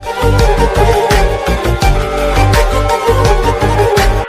A high-octane, powerful instrumental